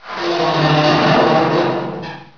doors
metal1.wav